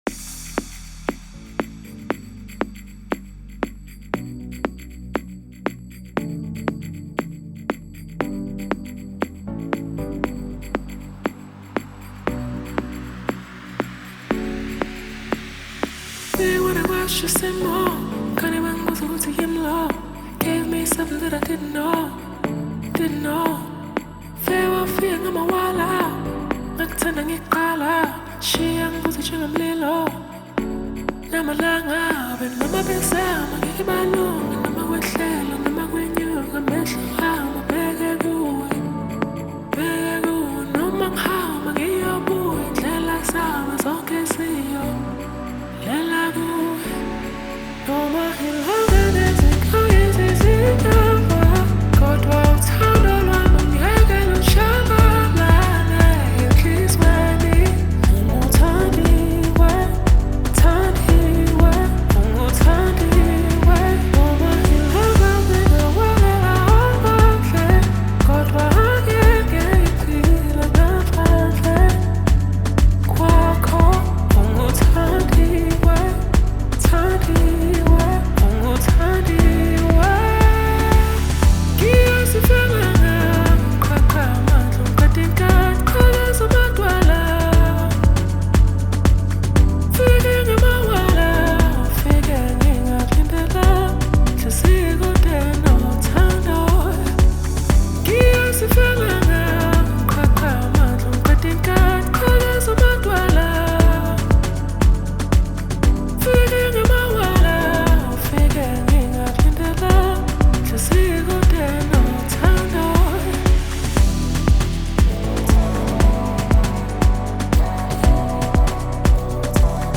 smooth vocals, the catchy hooks
blends Afrobeat with contemporary sounds
With its high energy tempo and catchy sounds